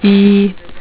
Real "eee" Sound
eee.wav